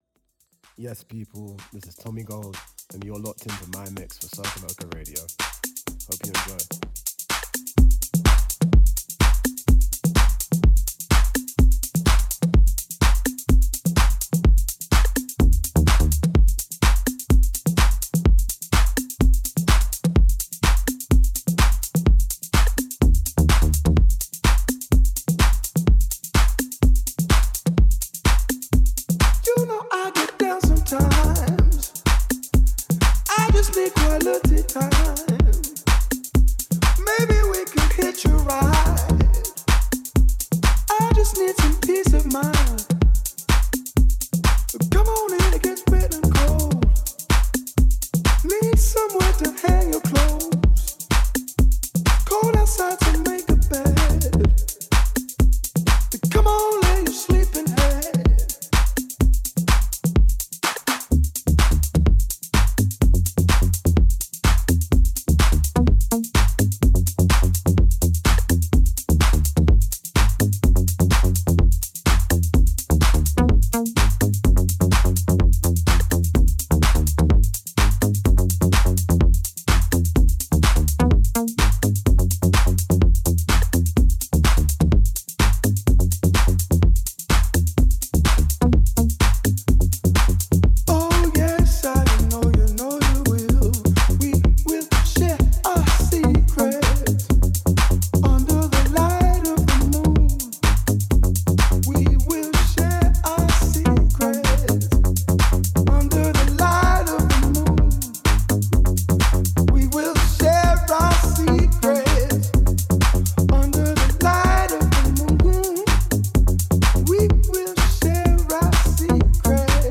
Bringing you new mixes from the best DJs in the world.